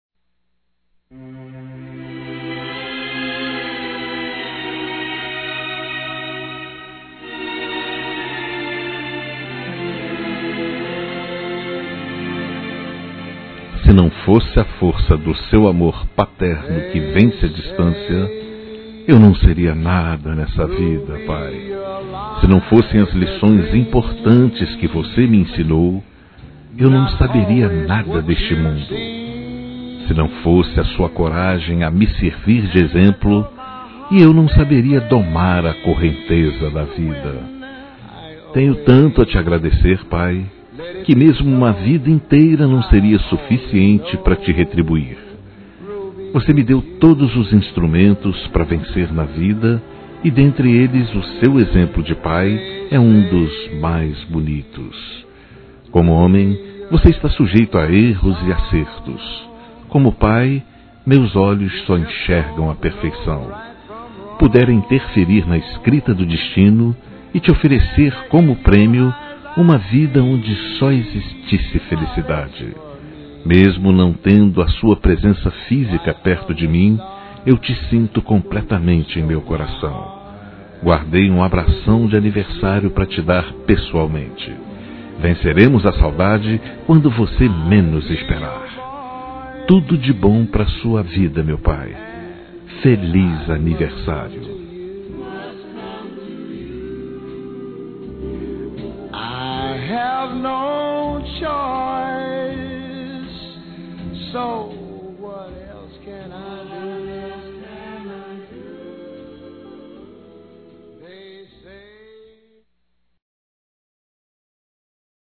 Telemensagem de Aniversário de Pai – Voz Masculina – Cód: 1513 Distante